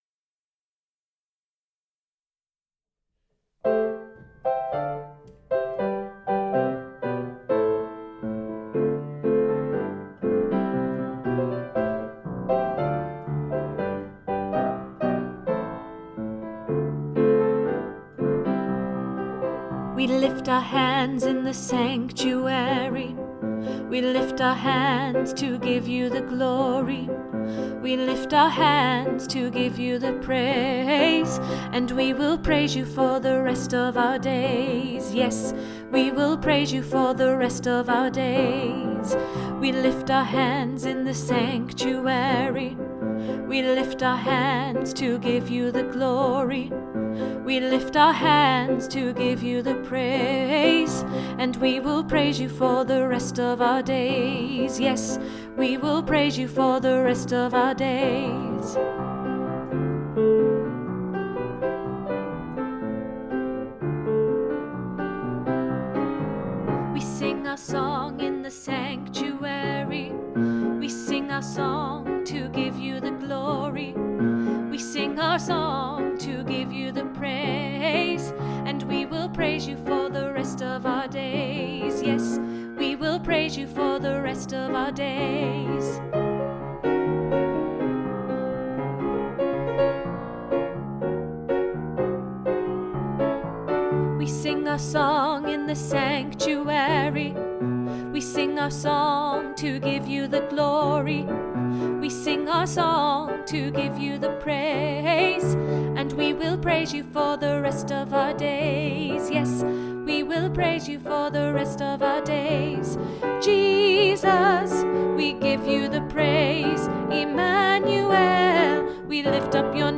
In the Sanctuary Alto